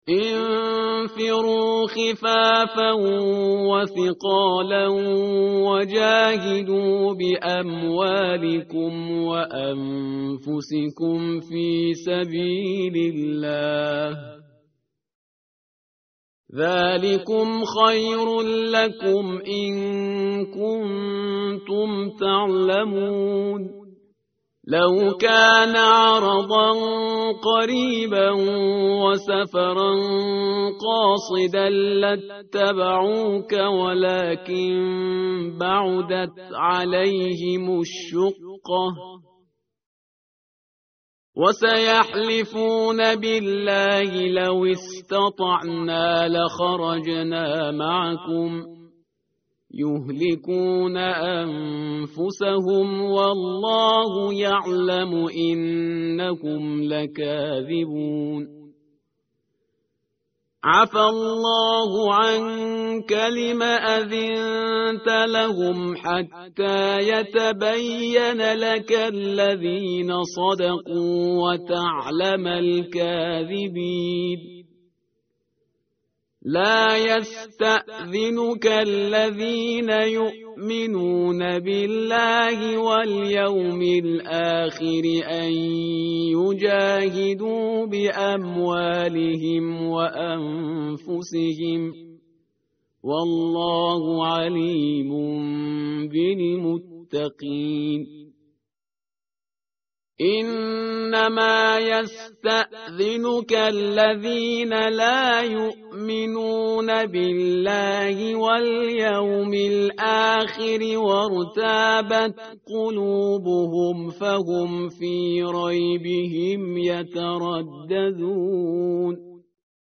متن قرآن همراه باتلاوت قرآن و ترجمه
tartil_parhizgar_page_194.mp3